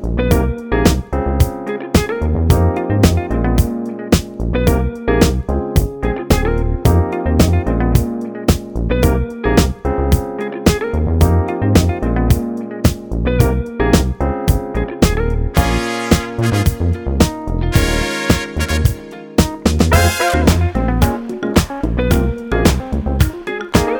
no Backing Vocals Soul / Motown 3:46 Buy £1.50